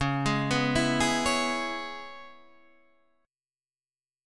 C#m7 chord